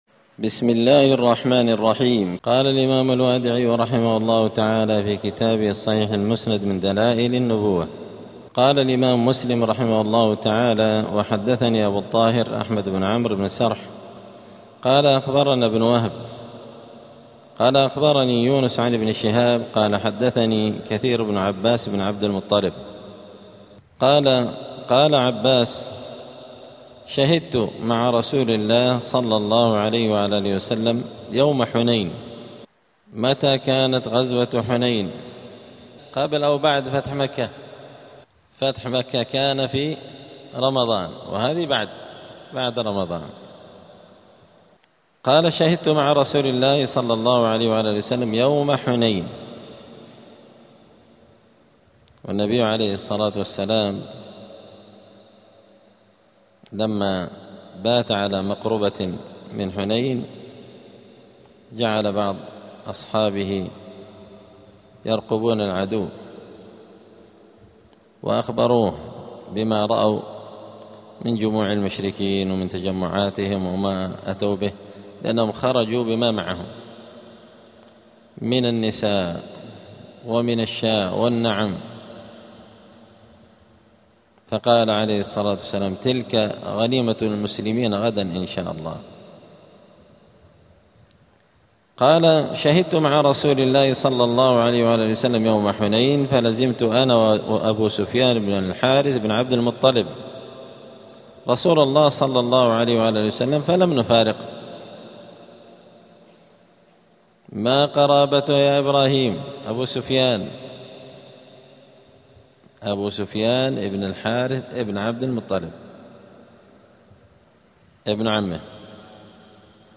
*الدرس الحادي عشر (11) {تابع فصل في صفة رسول صلى الله عليه وسلم الخَلْقِية والخُلُقِية}.*